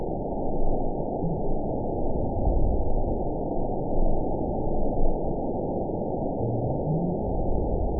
event 912549 date 03/29/22 time 08:31:28 GMT (3 years, 1 month ago) score 9.64 location TSS-AB02 detected by nrw target species NRW annotations +NRW Spectrogram: Frequency (kHz) vs. Time (s) audio not available .wav